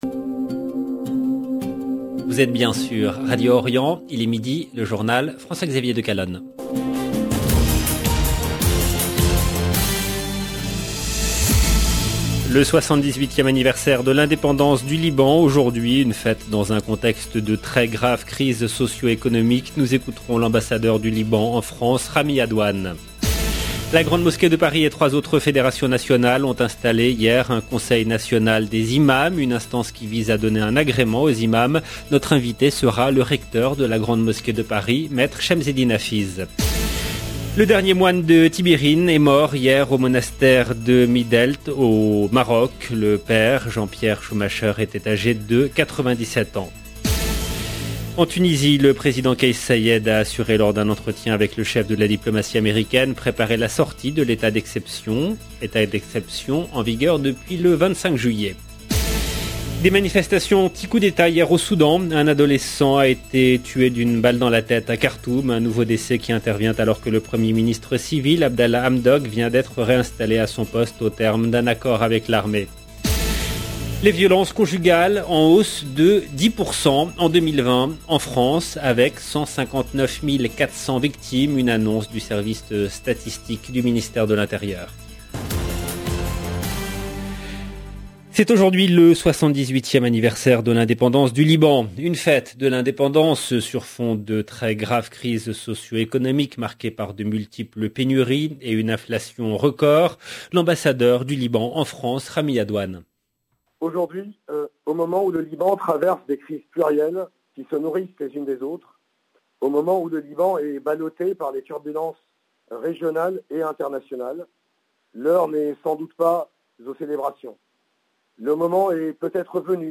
LE JOURNAL DE MIDI EN LANGUE FRANCAISE DU 22/11/21
Nous écouterons l’ambassadeur du Liban en France, Rami Adwan. La Grande mosquée de Paris et trois autres fédérations nationales ont installé hier un Conseil national des imams.